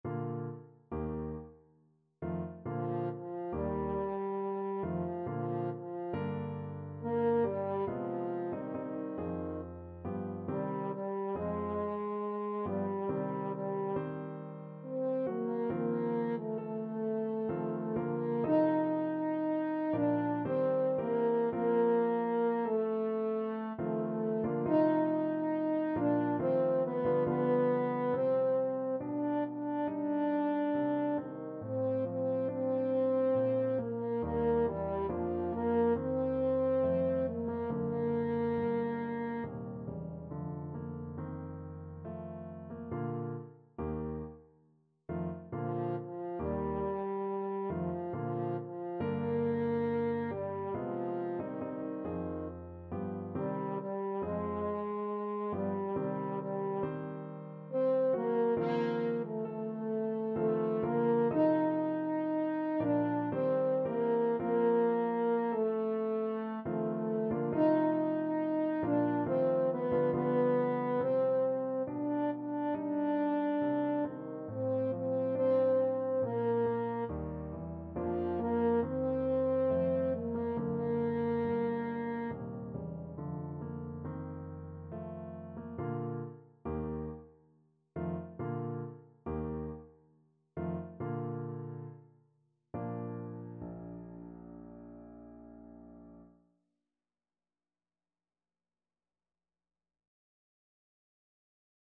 French Horn
Bb major (Sounding Pitch) F major (French Horn in F) (View more Bb major Music for French Horn )
3/4 (View more 3/4 Music)
Larghetto =69
Eb4-Eb5
Classical (View more Classical French Horn Music)